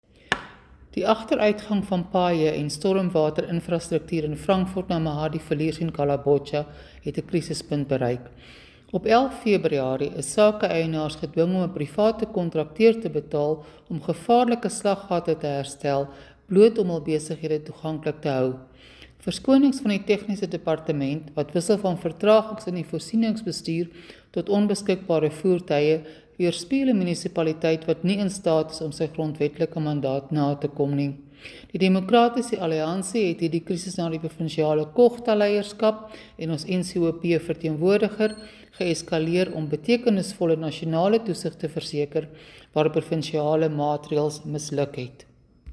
Afrikaans soundbites by Cllr Suzette Steyn and